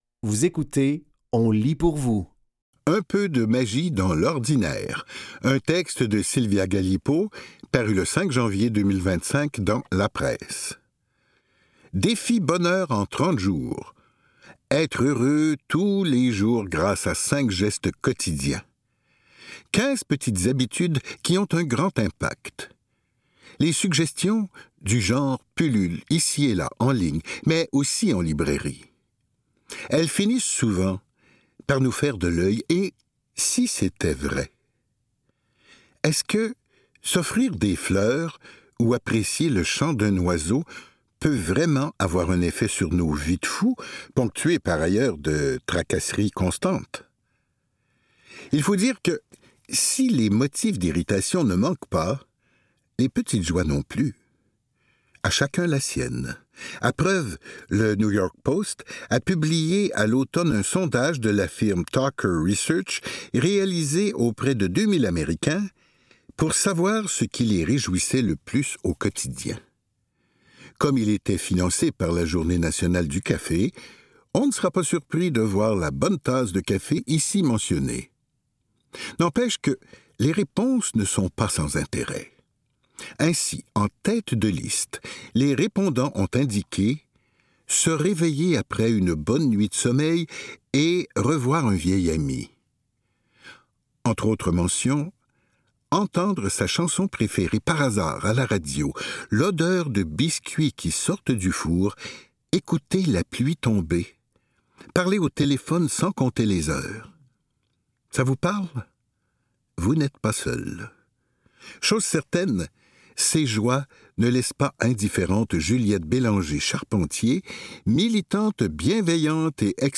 Dans cet épisode de On lit pour vous, nous vous offrons une sélection de textes tirés des médias suivants : La Presse, Le Journal de Montréal et RCI.